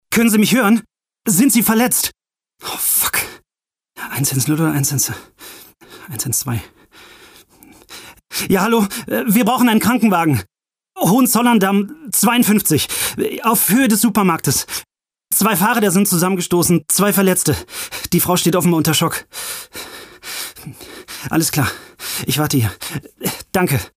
Sorry, Dein Browser unterstüzt kein HTML5 Brief USC Gute Nacht - Hörspiel Notruf - Hörspiel Eisessen - Trickstimme Schatzkarte - Sprache & Gesang Runaway - Gesang Bitcoins - Werbung